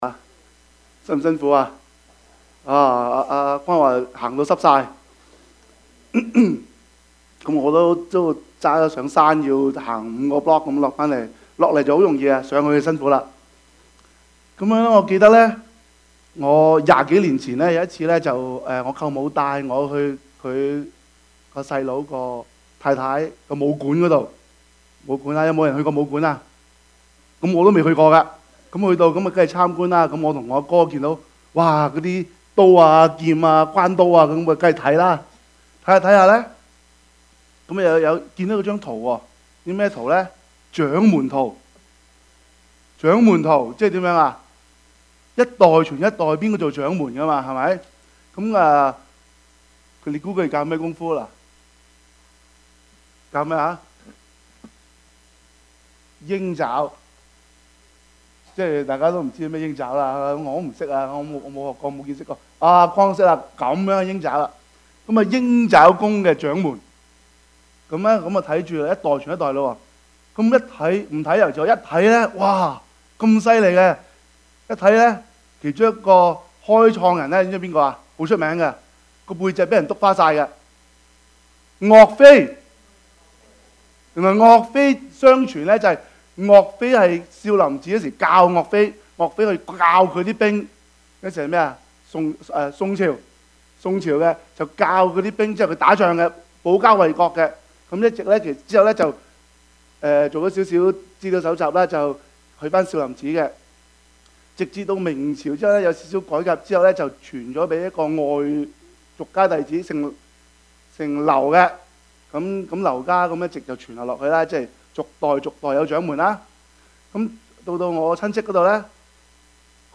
Service Type: 主日崇拜
Topics: 主日證道 « 哀莫大於心死 是人是獸 »